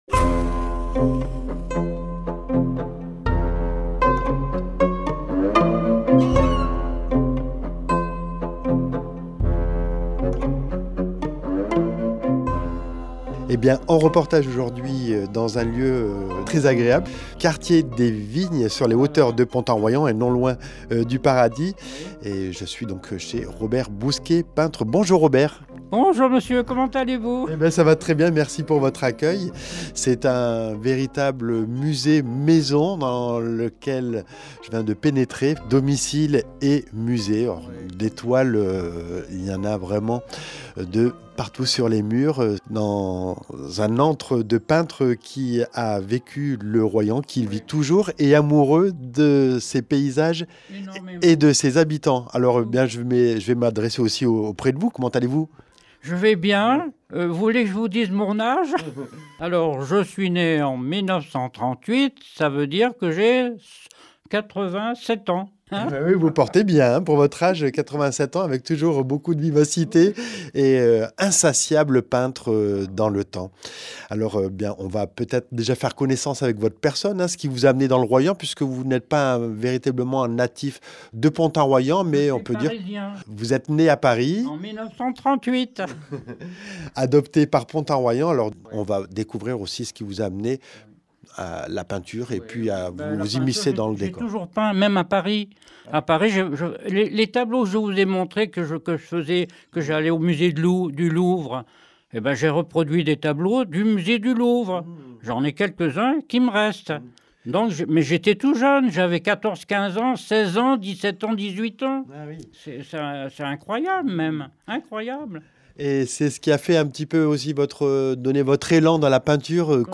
Entretien avec un personnage haut en couleurs et insatiable adepte du pinceau pour des représentations de la vie qui nous entoure, par ses paysages et personnages.